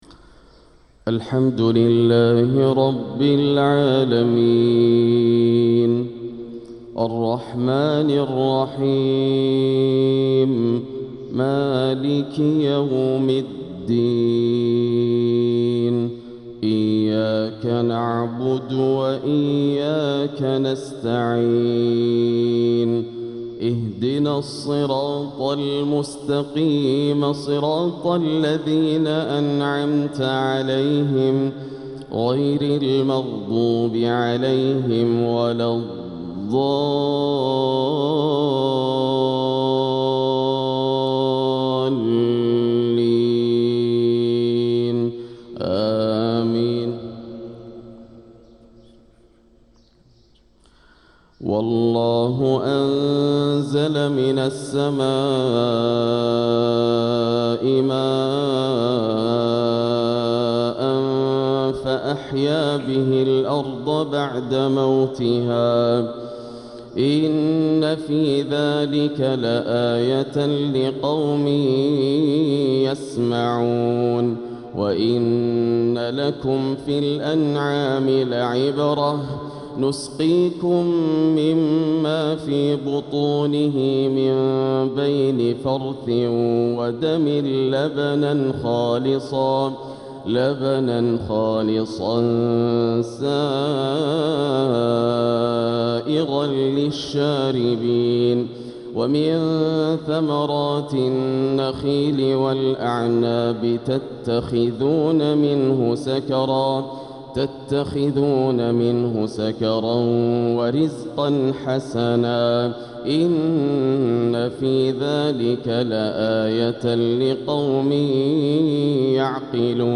فجر الثلاثاء 5-8-1446هـ | من سورة النحل 65-79 | Fajr prayer from Surat an-Nahl 4-2-2025 > 1446 🕋 > الفروض - تلاوات الحرمين